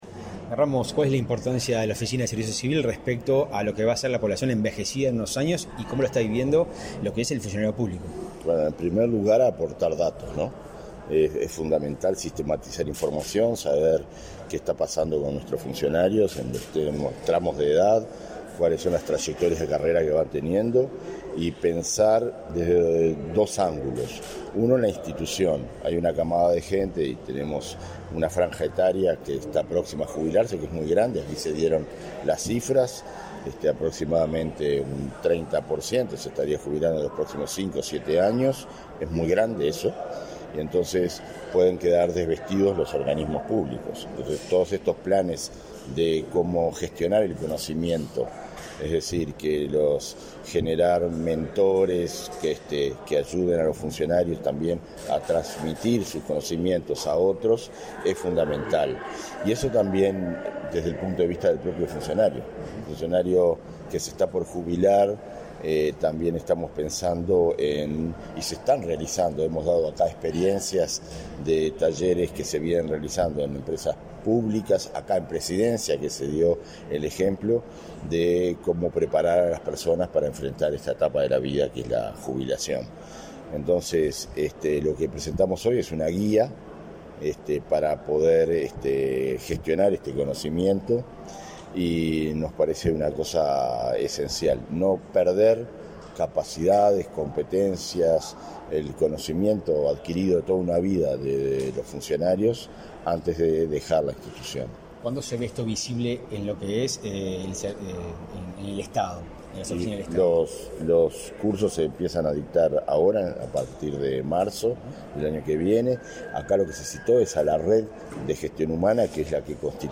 Entrevista al director de la ONSC, Conrado Ramos
Entrevista al director de la ONSC, Conrado Ramos 16/12/2022 Compartir Facebook X Copiar enlace WhatsApp LinkedIn La Oficina Nacional del Servicio Civil (ONSC) presentó, este 16 de diciembre, una guía organizacional para elaborar programas de preparación para el retiro por jubilación. Tras finalizar la actividad, el director de la ONSC, Conrado Ramos, realizó declaraciones a Comunicación Presidencial.